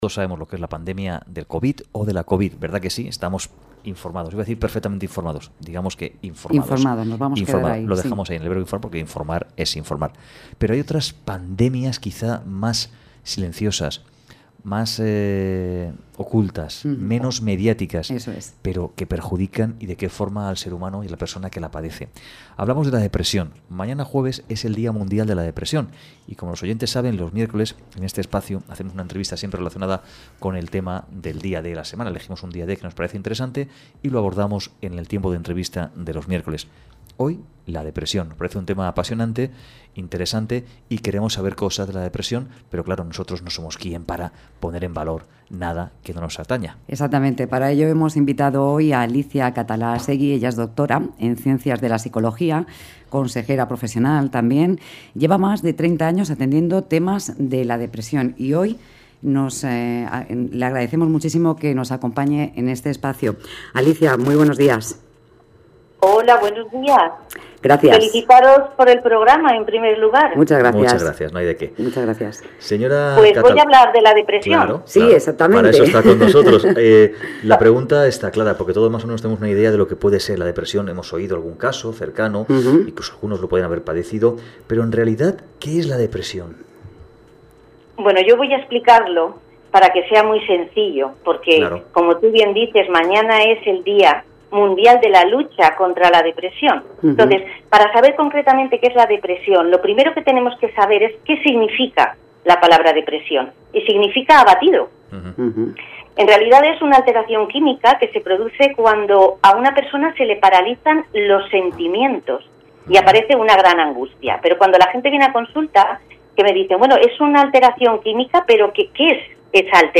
CHARLA_RADIO_DEPRESION.mp3